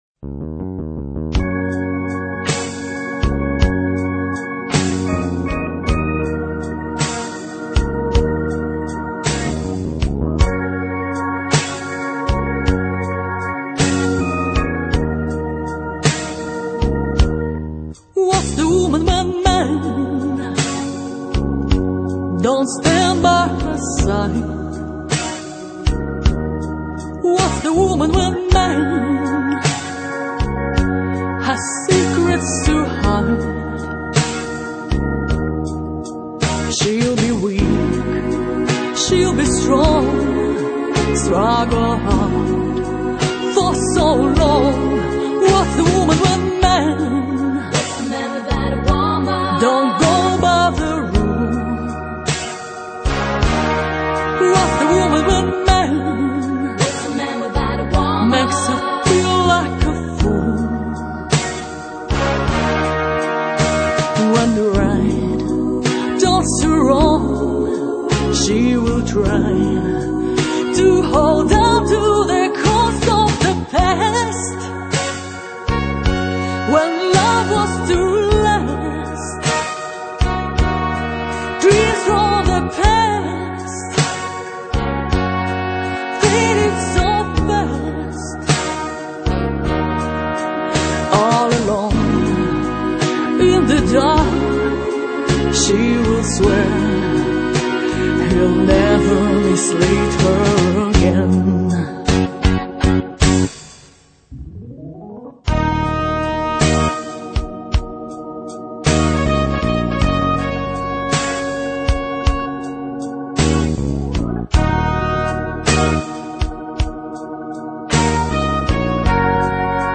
...чарующе, уносяще, интеллигентно...